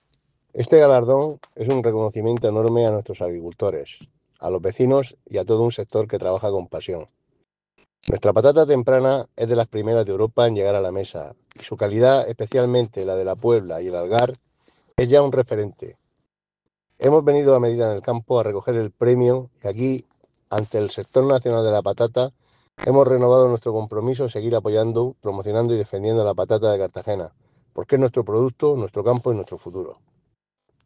Enlace a Declaraciones de José Ramón Llorca sobre promoción de la patata local